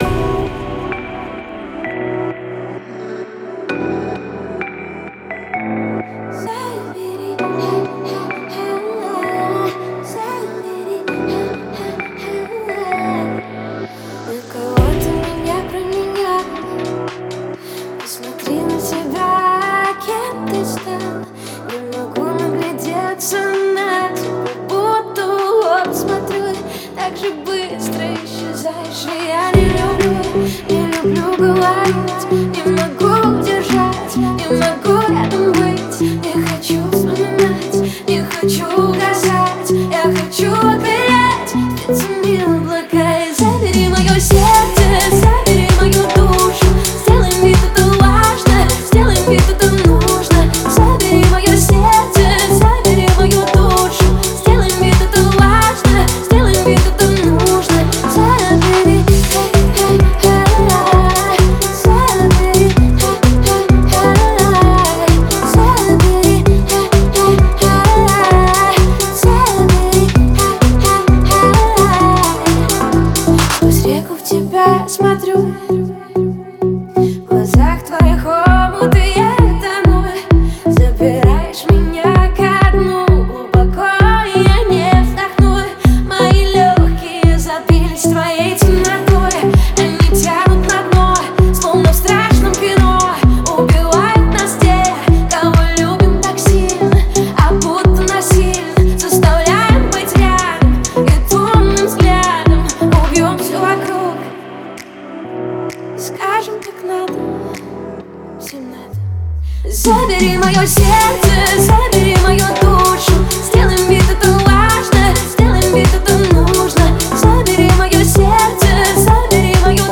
который сочетает в себе элементы электроники и поп-музыки.